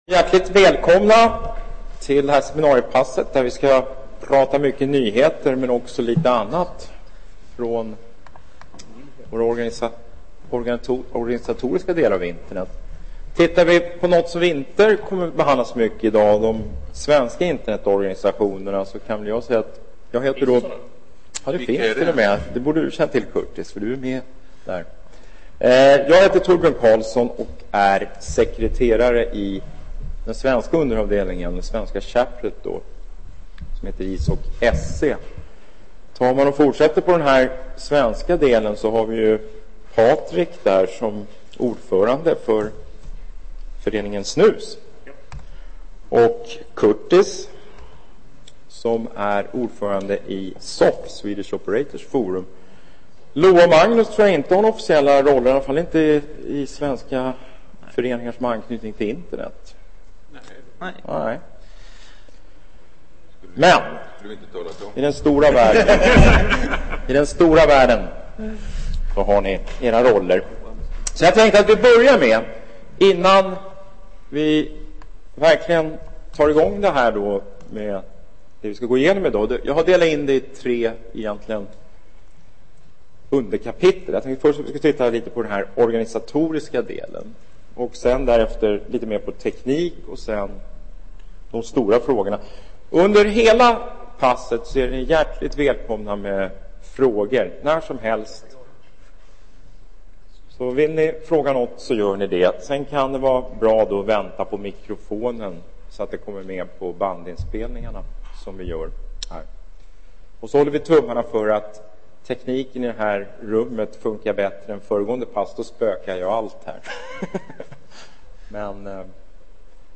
En kvartett svenskar med centrala roller inom Internets standardiseringsarbete intervjuas om den internationella utvecklingen och om vart Internet �r p� v�g. Det kommer ocks� att finnas gott om tid f�r fr�gor fr�n publiken.